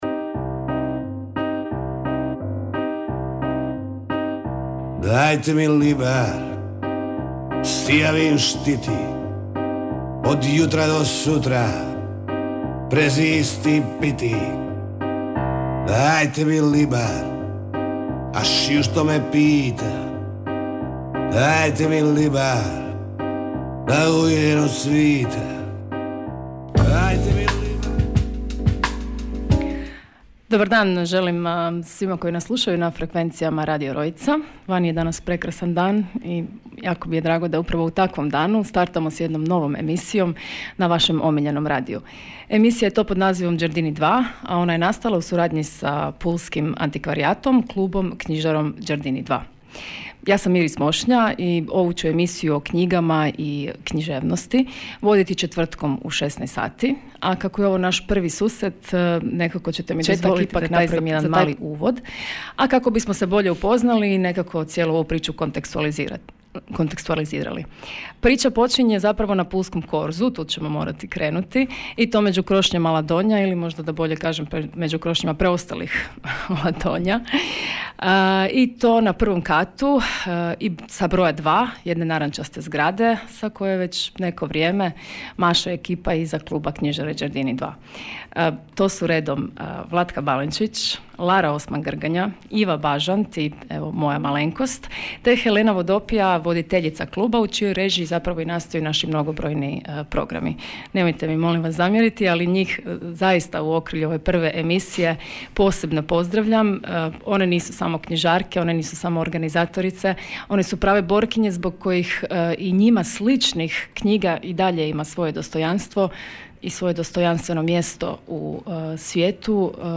Ciklus razgovora na Radio Rojcu otvorila je naša Puležanka